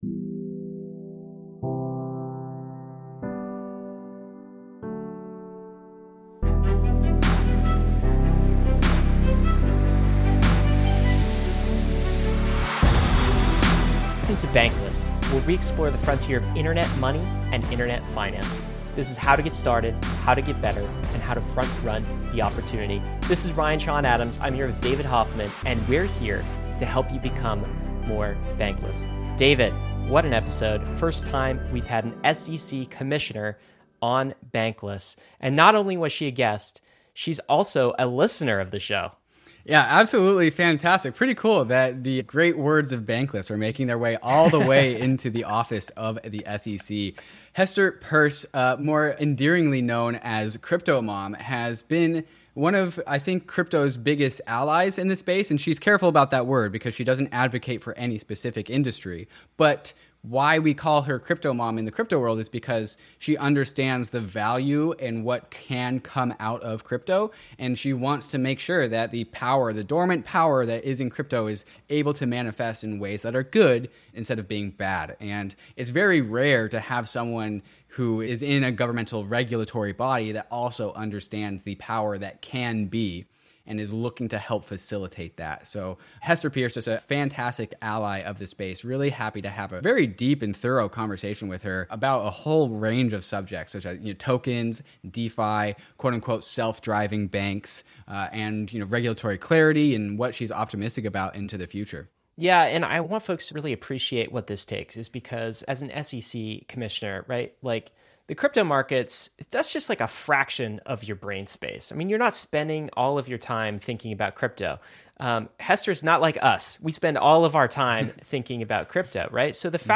Bankless Fan and SEC Commissioner Hester Peirce comes on the podcast to discuss tokens, DeFi, 'self-driving banks,' and regulation.